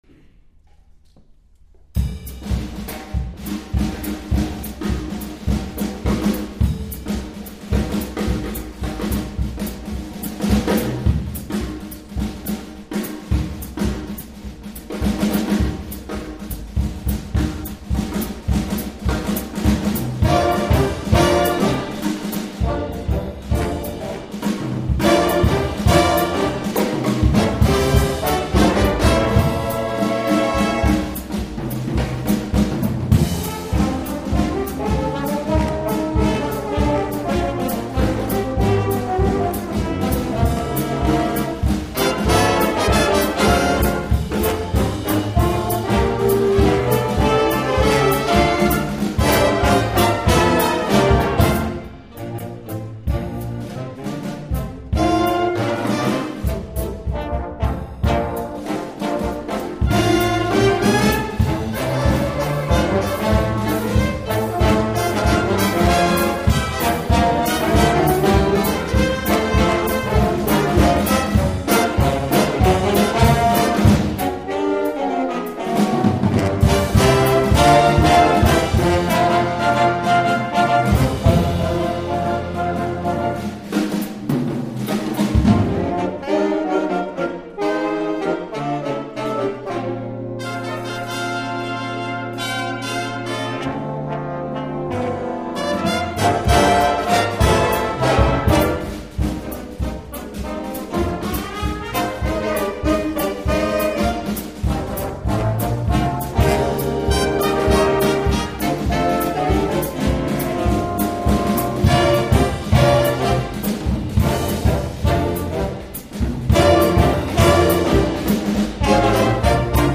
Jazz Ensemble recordings :: Music :: Swarthmore College